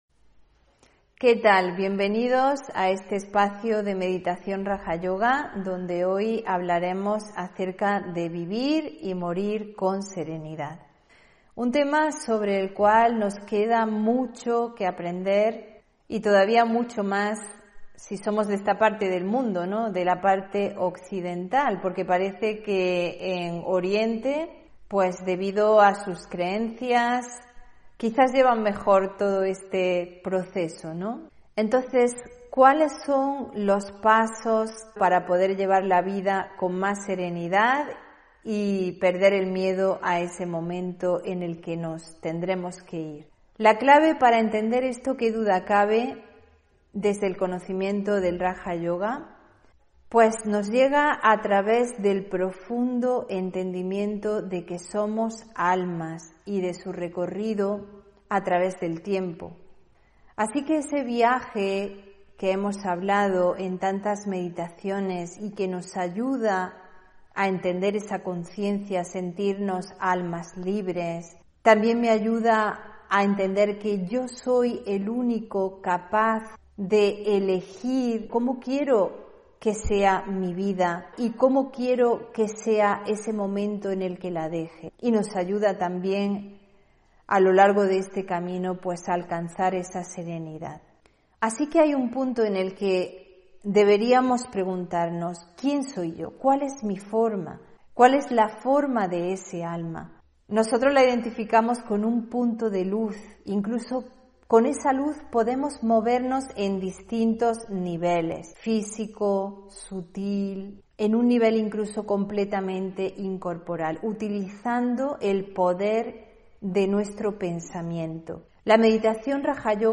Meditación y conferencia: Vivir y morir con serenidad (25 Enero 2022)